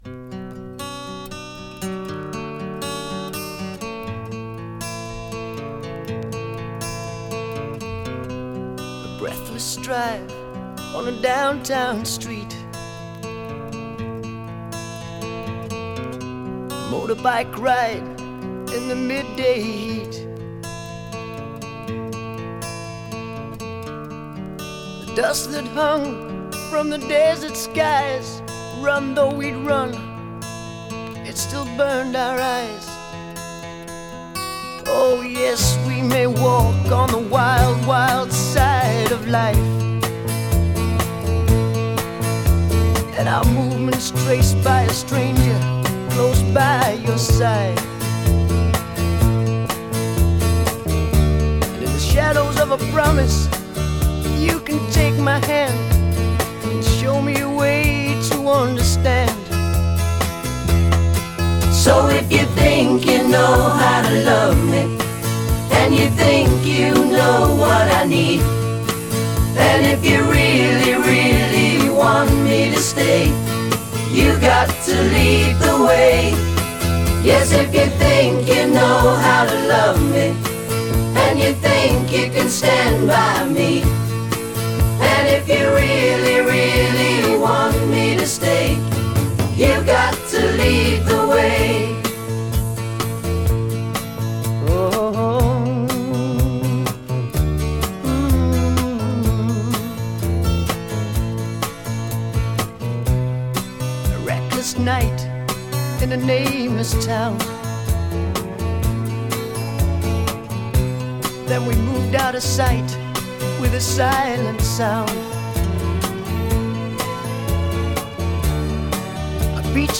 Genre: Pop Rock.